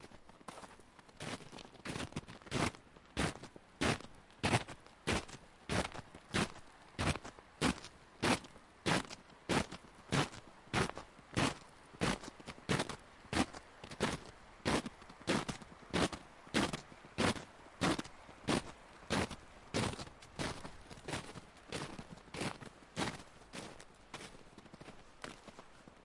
河东雪地行走" 脚步雪地05
标签： 脚步 现场录音 户外
声道立体声